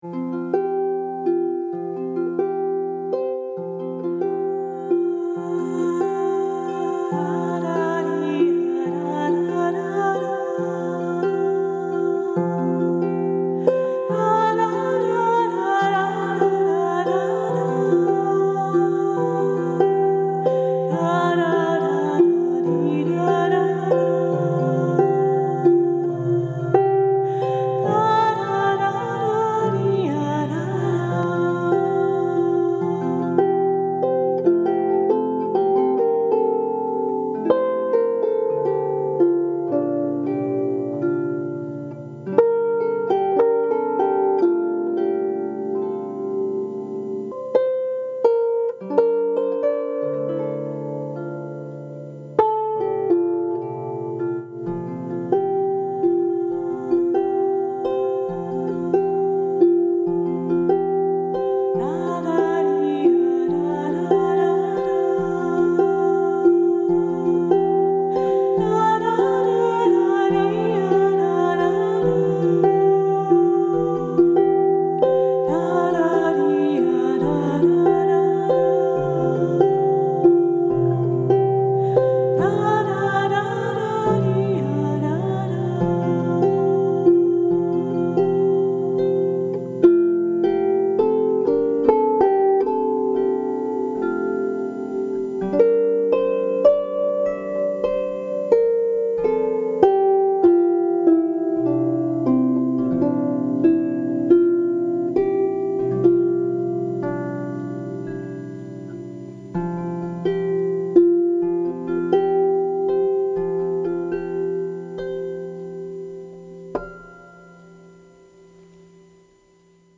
Ave Regina Caelorum (Hail, Queen of Heaven) - An ancient Marian antiphon arranged for harp by celtic harpist